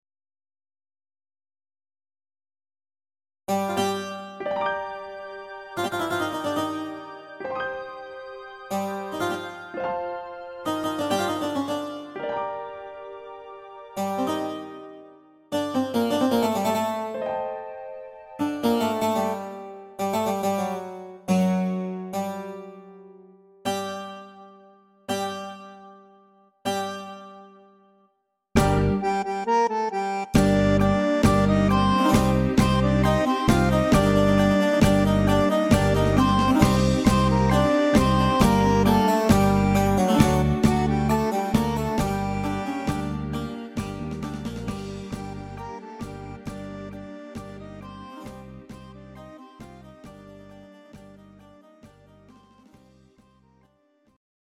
Greek Zeimpekiko